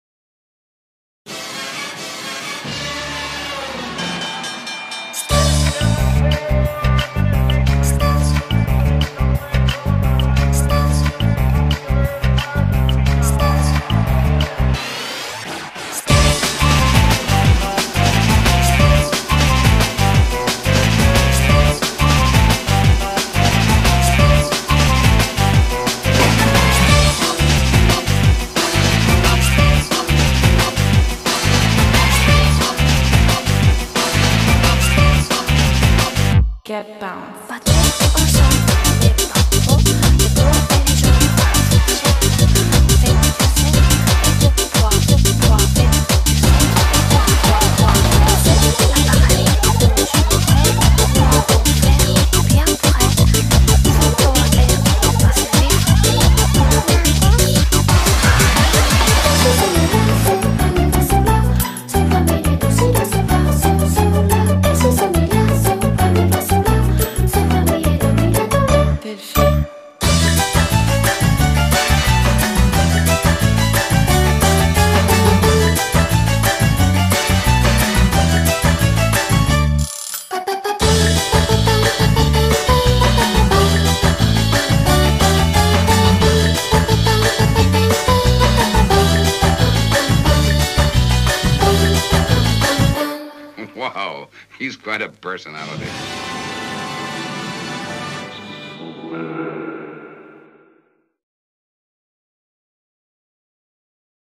BPM89-178
Audio QualityCut From Video